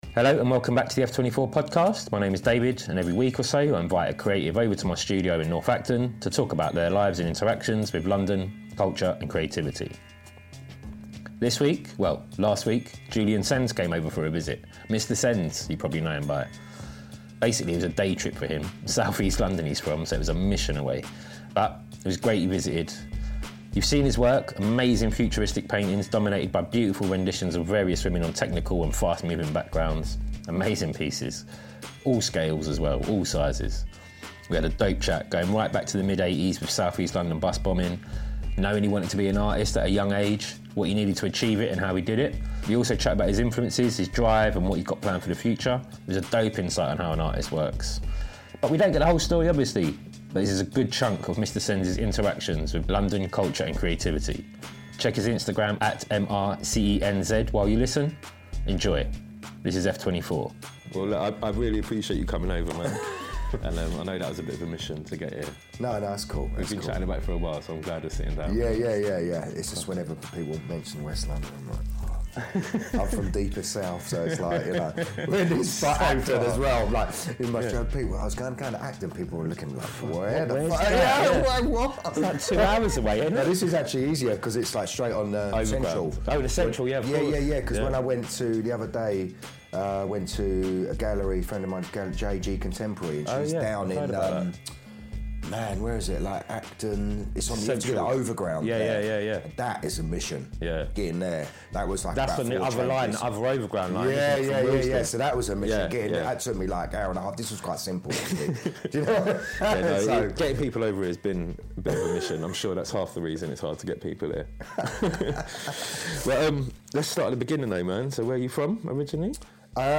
We had a dope chat, going right back to the mid-eighties and early bus bombing on se busses, knowing he wanted to be an artist, what was needed to achieve that and how he did it..